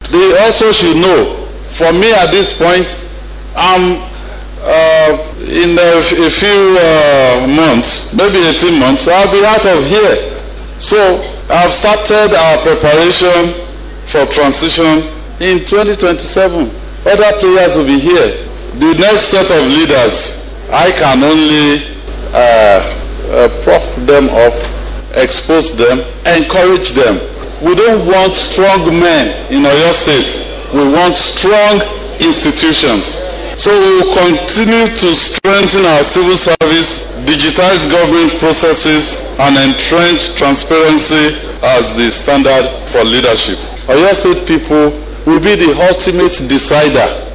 The governor disclosed this at a gathering in Ibadan, noting that he has less than 18 months left in office.